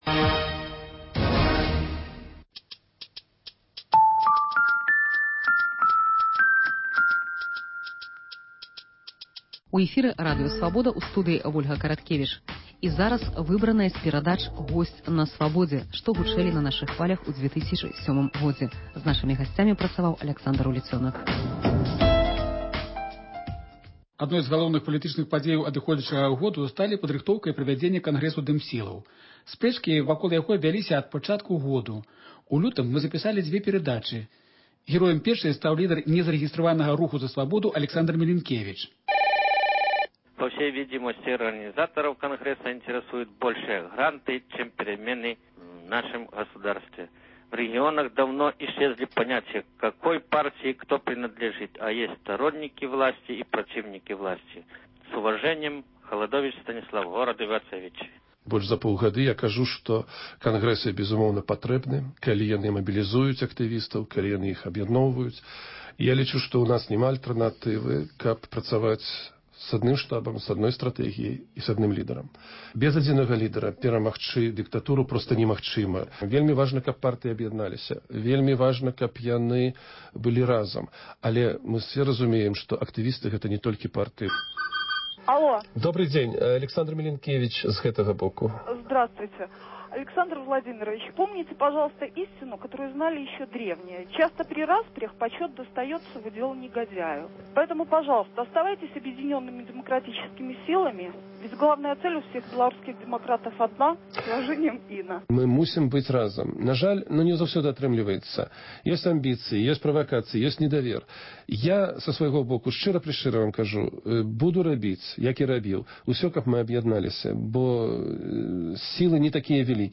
Анталёгія 2007 году (Госьць у студыі адказвае на лісты, званкі, СМС-паведамленьні)